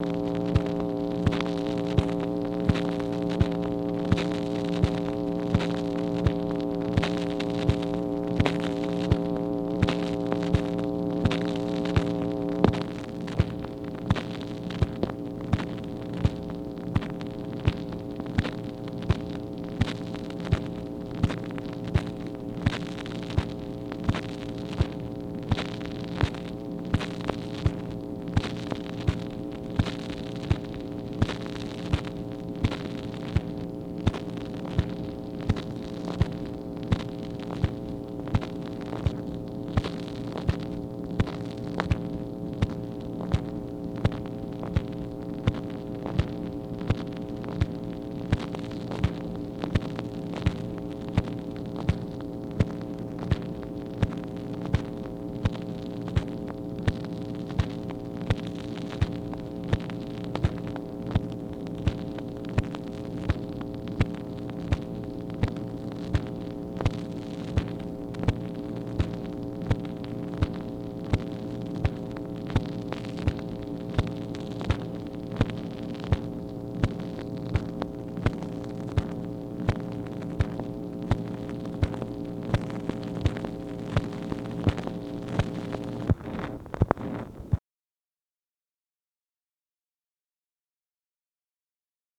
MACHINE NOISE, August 22, 1964
Secret White House Tapes | Lyndon B. Johnson Presidency